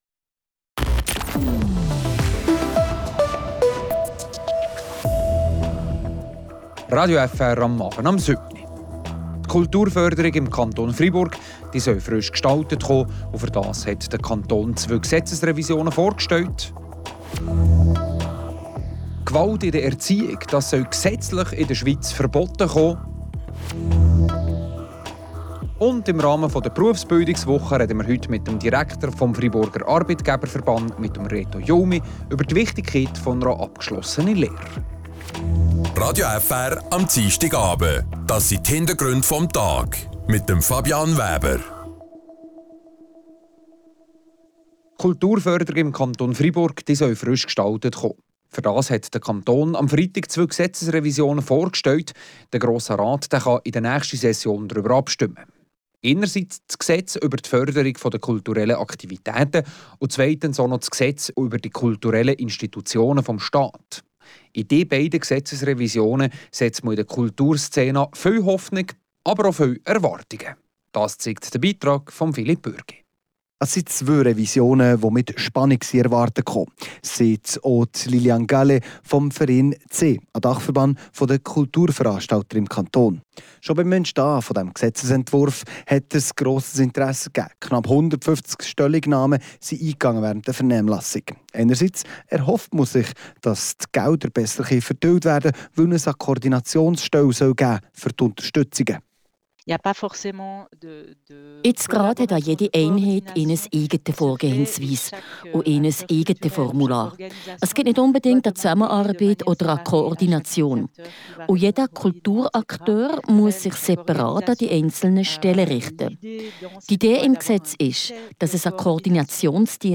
Wir hören dazu die Motionärin, Christine Bulliard-Marbach.